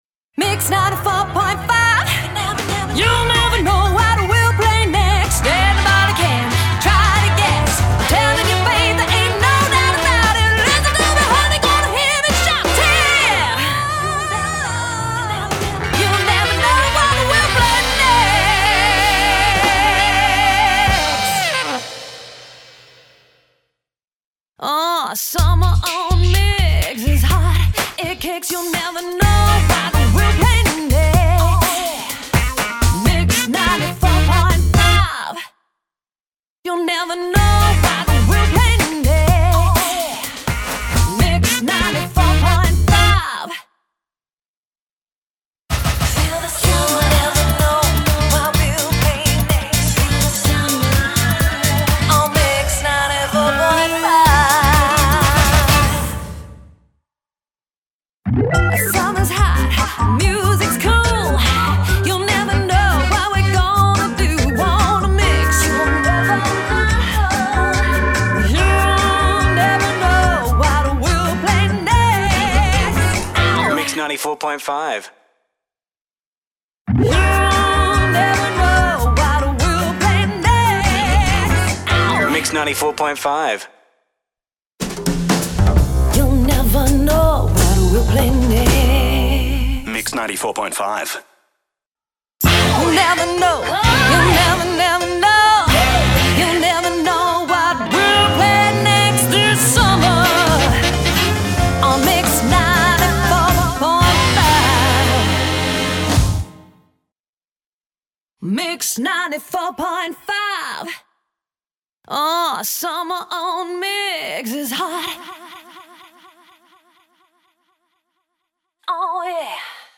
Session Singing & Vocal Solos